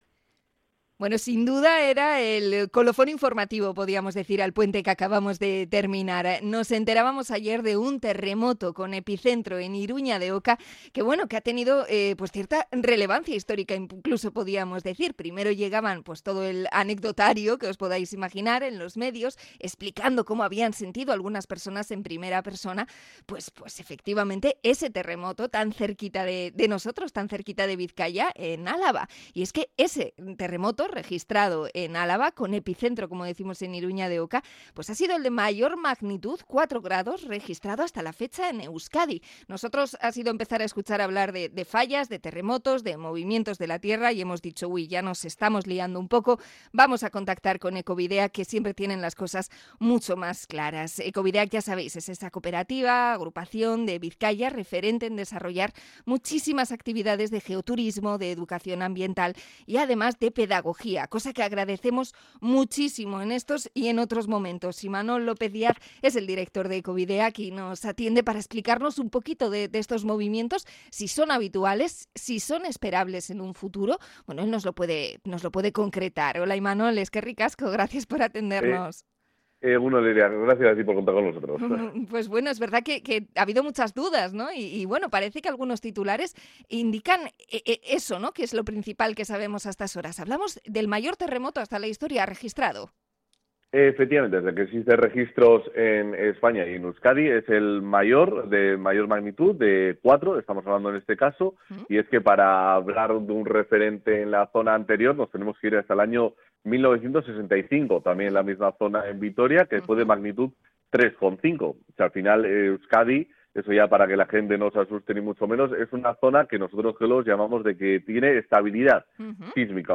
Entrevista a Ekobideak por el seísmo de ayer